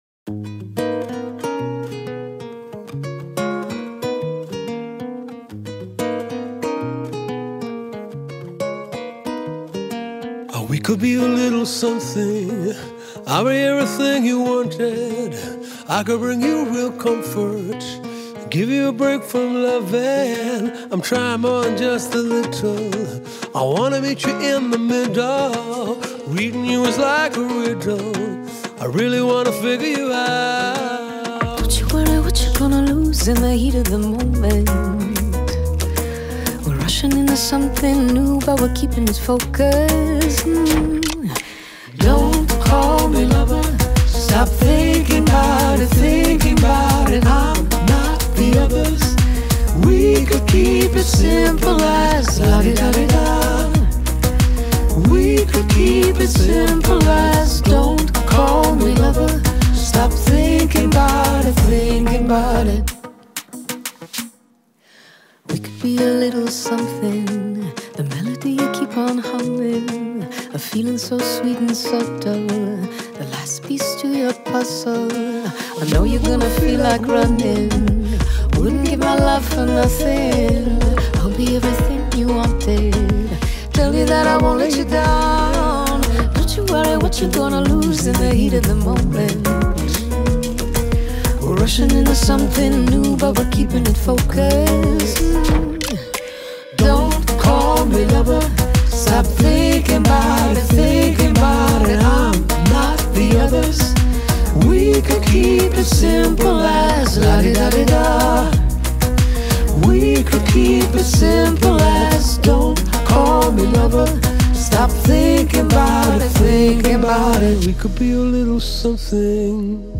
Pop, Rock, Jazz, Reggae